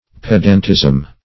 pedantism - definition of pedantism - synonyms, pronunciation, spelling from Free Dictionary
Search Result for " pedantism" : The Collaborative International Dictionary of English v.0.48: Pedantism \Ped"ant*ism\, n. The office, disposition, or act of a pedant; pedantry.